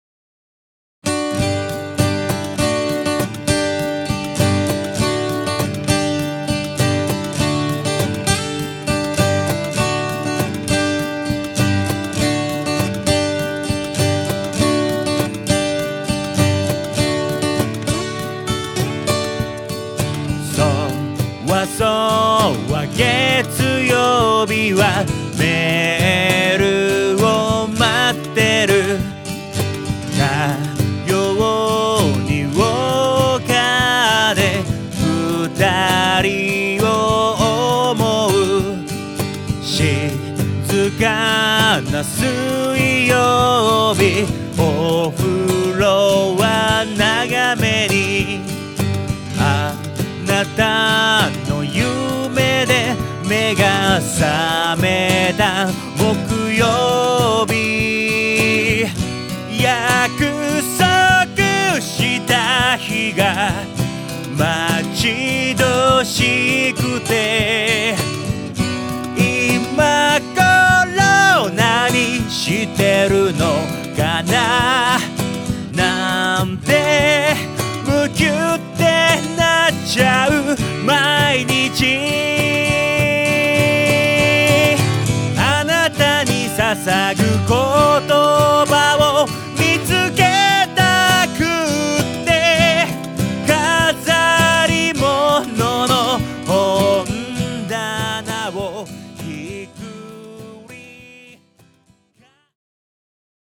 独自に厳選を重ねた8曲を怒涛のロックアレンジ！
ヴォーカル